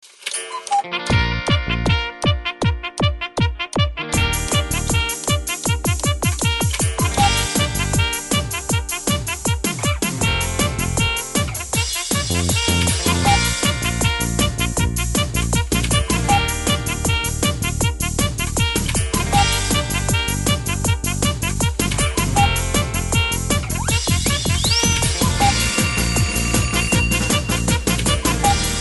• Качество: 128, Stereo
труба
Кукушка плюс горн)